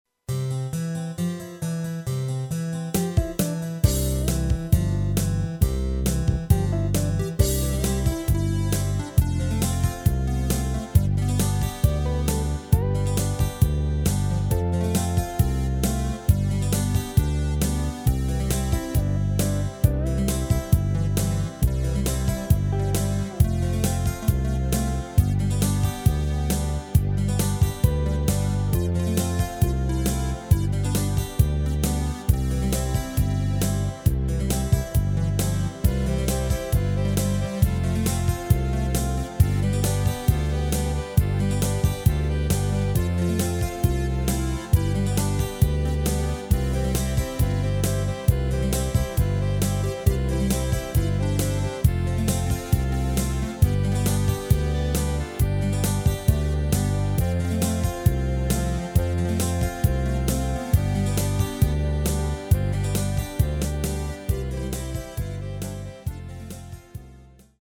Rubrika: Pop, rock, beat
Předehra: kytara